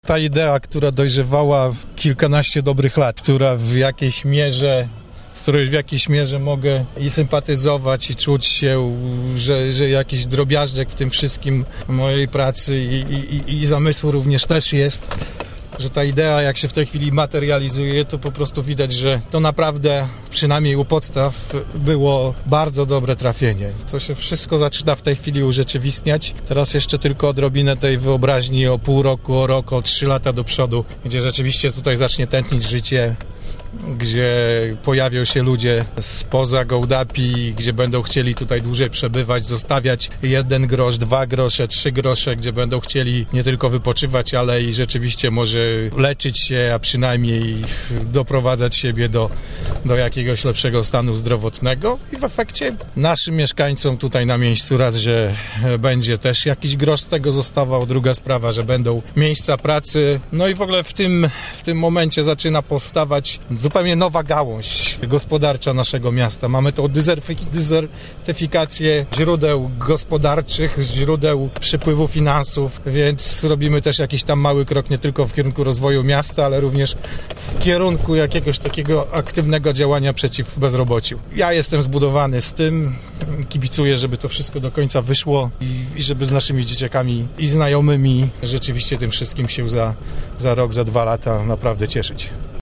mówi Piotr Rant, wiceprzewodniczący Rady Miejskiej w Gołdapi